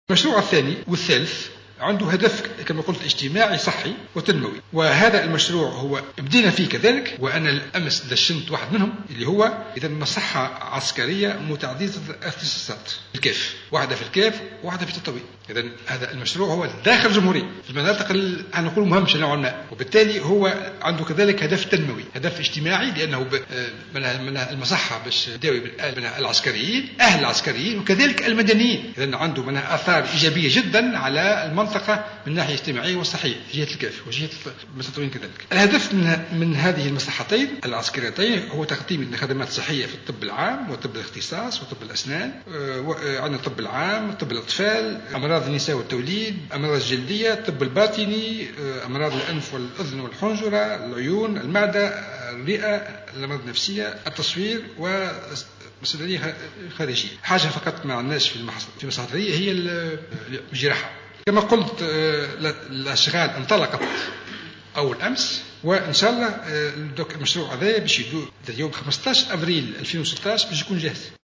قال وزير الدفاع الوطني فرحات الحرشاني في ندوة صحفية عقدت اليوم الخميس لتقديم أولويات الوزارة للمائة يوم الأولى بمقر رئاسة الحكومة بالقصبة إن وزارته شرعت في إطار مزيد الإحاطة الصحية بالعسكريين وبعائلاتهم في أشغال إحداث مصحتين متعددتي الاختصاصات في الكاف وتطاوين من المقرر أن تنتهي في شهر أفريل 2016.